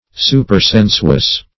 Search Result for " supersensuous" : The Collaborative International Dictionary of English v.0.48: Supersensuous \Su`per*sen"su*ous\, a. 1.